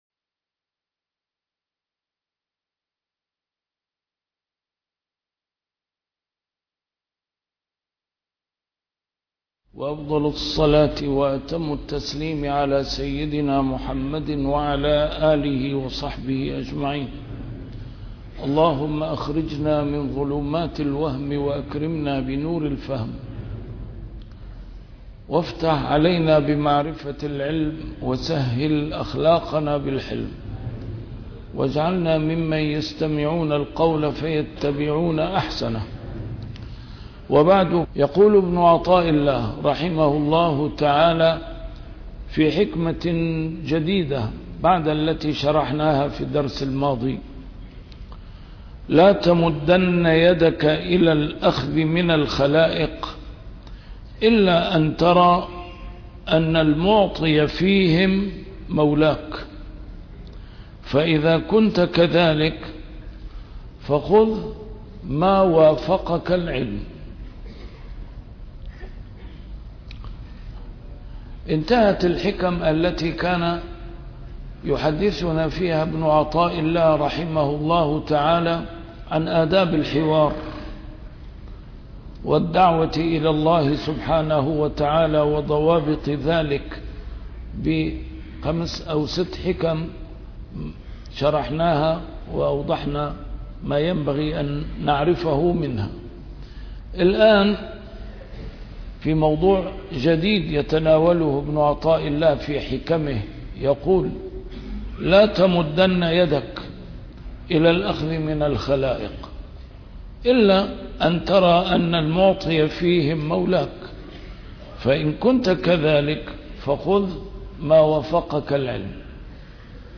A MARTYR SCHOLAR: IMAM MUHAMMAD SAEED RAMADAN AL-BOUTI - الدروس العلمية - شرح الحكم العطائية - الدرس رقم 210 شرح الحكمة رقم 189